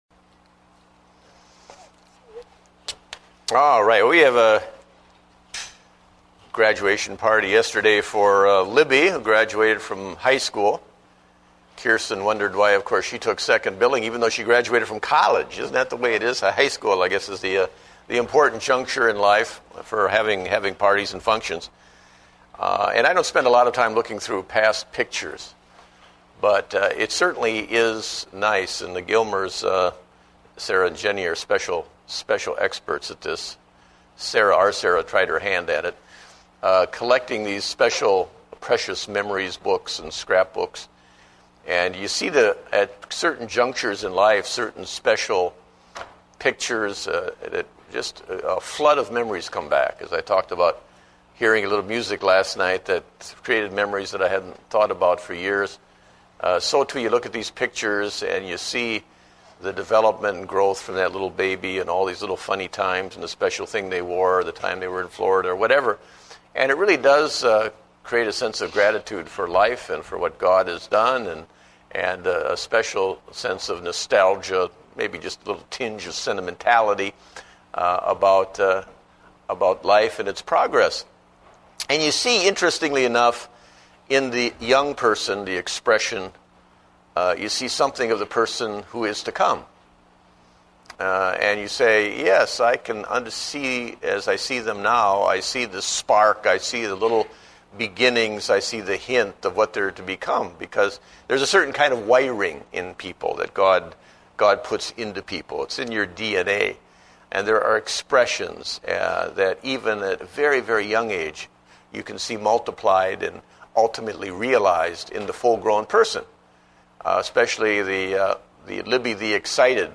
Date: June 14, 2009 (Adult Sunday School)